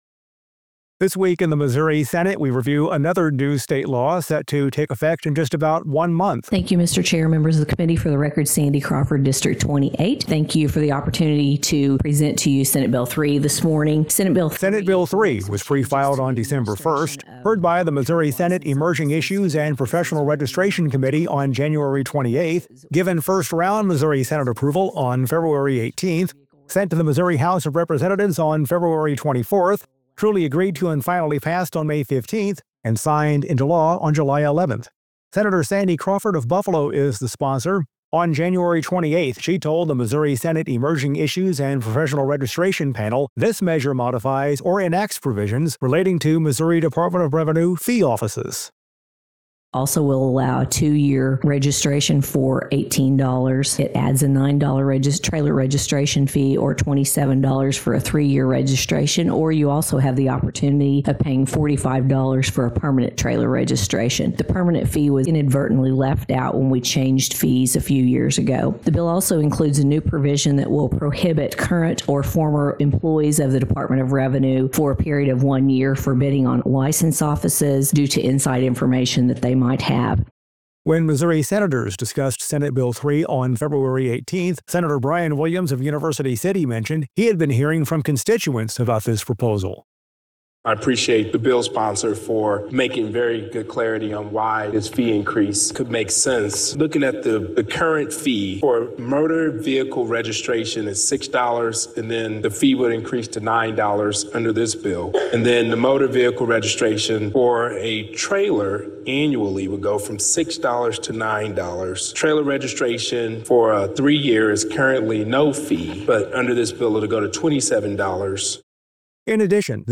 Every Friday, Senate Communications offers This Week in the Missouri Senate, a wrap up of the week’s actions that includes audio from floor debate, committee hearings and one-on-one interviews with the senators themselves.